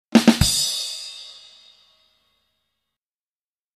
Download Drum Stick sound effect for free.
Drum Stick